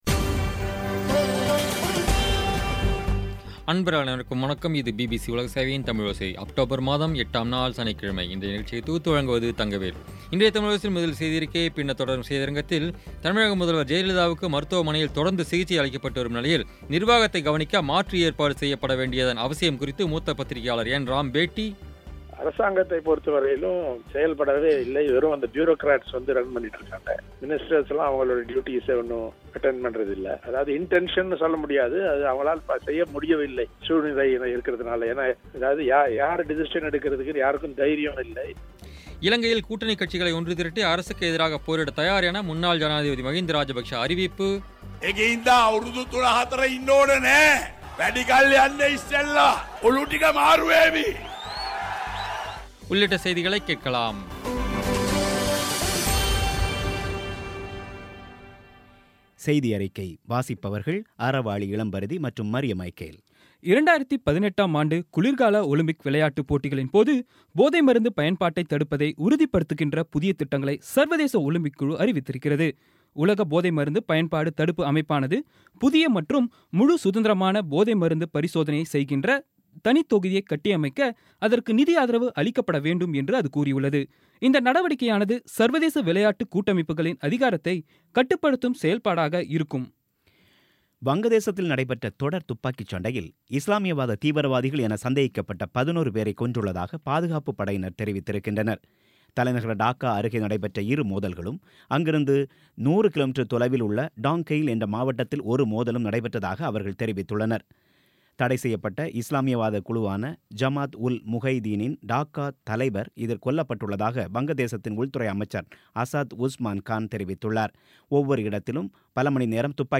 இன்றைய தமிழோசையில், தமிழக முதல்வர் ஜெயலலிதாவுக்கு மருத்துவமனையில் தொடர்ந்து சிகிச்சையளிக்கப்பட்டு வரும் நிலையில், நிர்வாகத்தைக் கவனிக்க மாற்று ஏற்பாடு செய்யப்பட வேண்டியதன் அவசியம் குறித்து மூத்த பத்தரிகையாளர் என். ராம் பேட்டி இலங்கையில், கூட்டணிக் கட்சிகளை ஒன்றுதிரட்டி, அரசுக்கு எதிராகப் போரிடத் தயார் என முன்னாள் ஜனாதிபதி மஹிந்த ராஜபக்ஷ அறிவிப்பு உள்ளிட்ட செய்திகளைக் கேட்கலாம்.